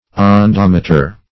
Search Result for " ondometer" : The Collaborative International Dictionary of English v.0.48: Ondometer \On*dom"e*ter\, n. [F. onde wave, L. unda + -mater.]